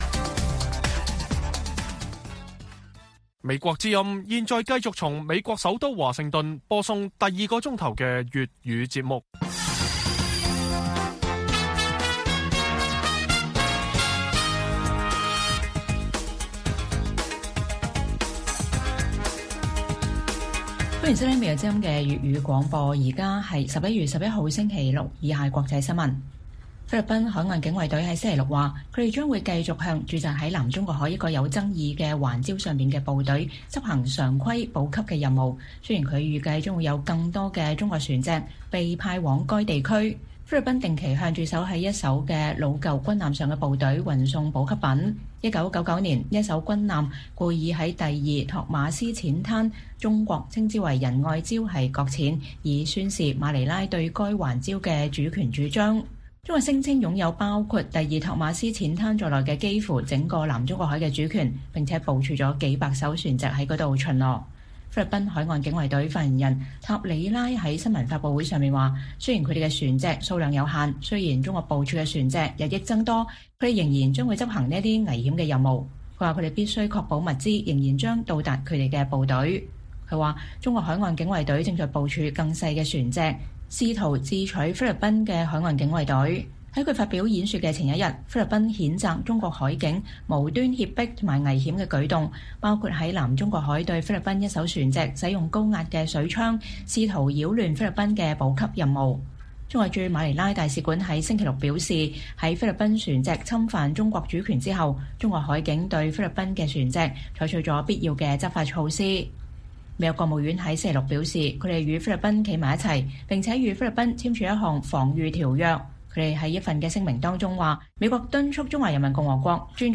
粵語新聞 晚上10-11點：菲律賓海警不顧中國船隻攔阻繼續在南中國海執行補給任務